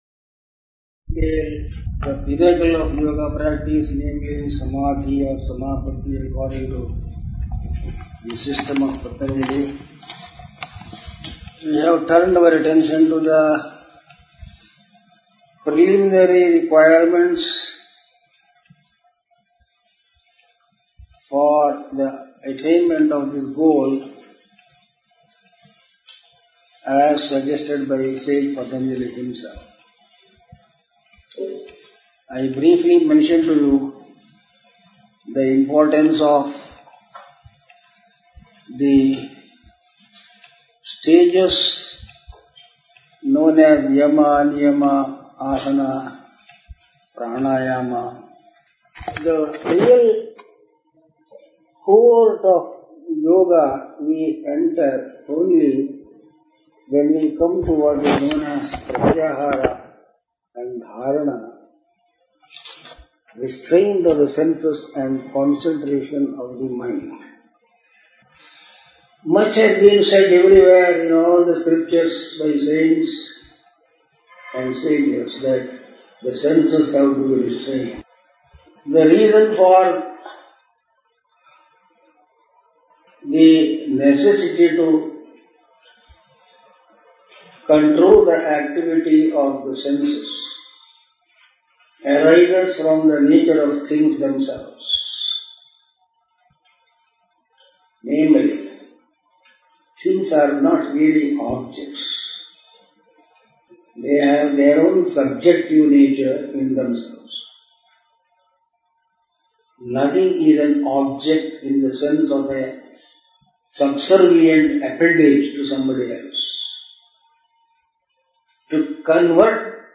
Swamiji speaks on Sense Restraint, Concentration and Meditation